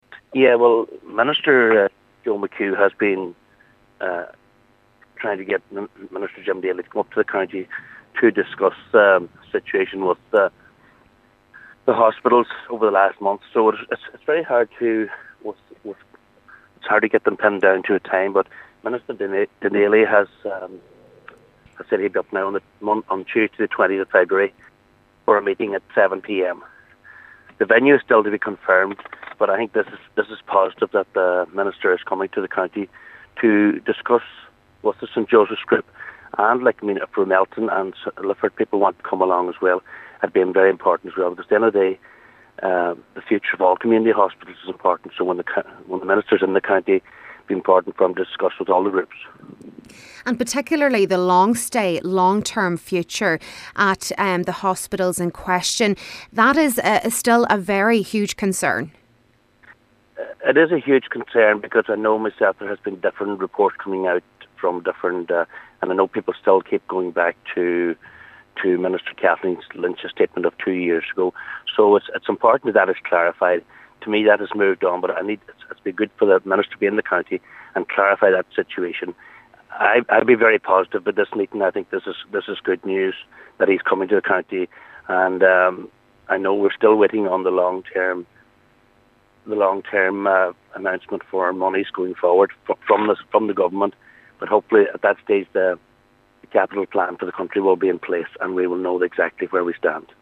Cllr Martin Harley is hopeful that the Minister will provide more clarity on various issues: